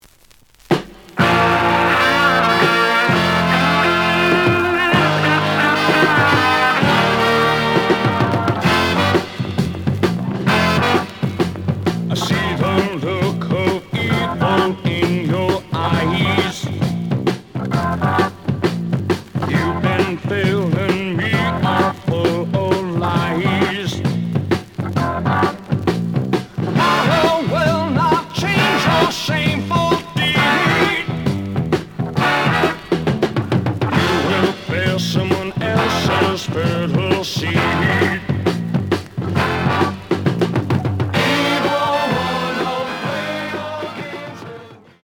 試聴は実際のレコードから録音しています。
●Genre: Rock / Pop